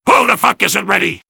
soldier_mvm_ask_ready01.mp3